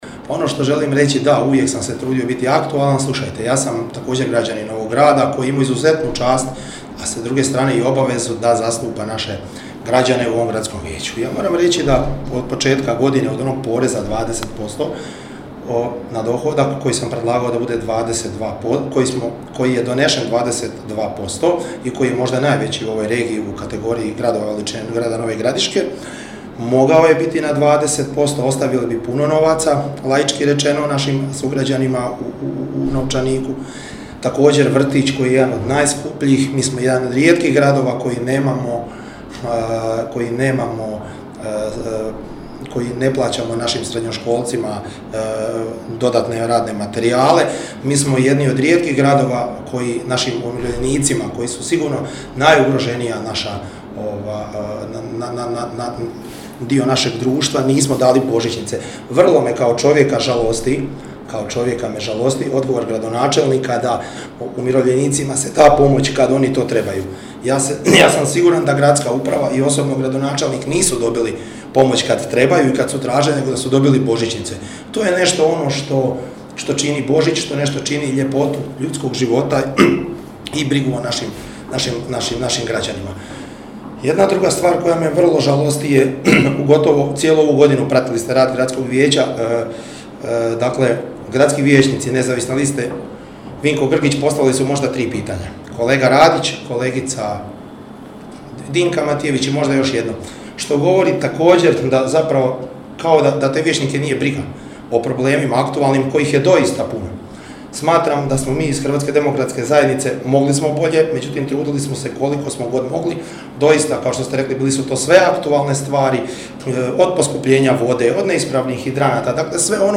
Neposredno prije današnje, 25. sjednice Gradskog vijeća Grada Nova Gradiška na kojoj se trebalo raspravljati o novom gradskom Proračunu, svoj stav o toj temi i funkcioniranju Gradskog vijeća u protekloj godini na tiskovnoj su konferenciji iznijeli vijećnici iz redova Hrvatske demokratske zajednice.
Javnosti se obratio i HDZ-ov vijećnik Tomislav Bećirević te apostrofirao neka pitanja. Cijena vrtića, božićnice umirovljenicima, sufinanciranje radnih materijala učenicima, teme su o kojima je progovorio.